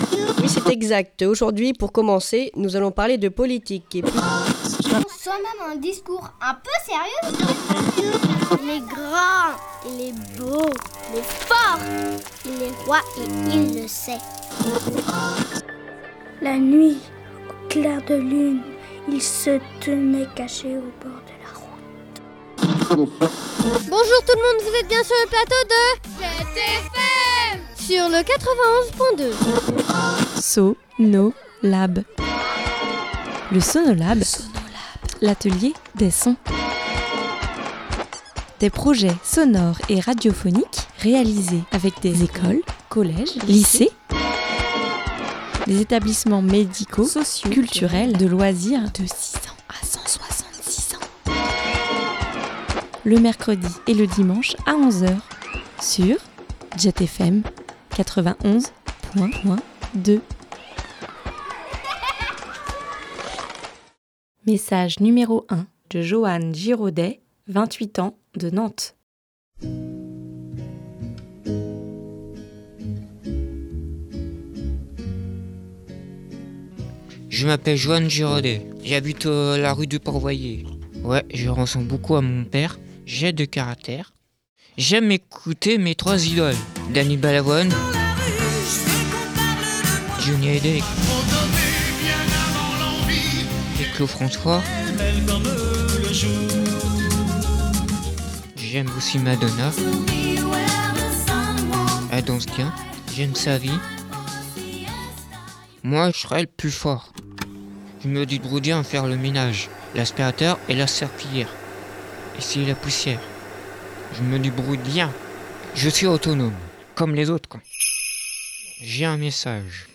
6 personnes du Foyer Saint Donatien vous livrent leurs messages ...
Puis ils sont venus 4 fois dans les studios de JET pour enregistrer leurs textes, des chansons, des bruitages, des dédicaces.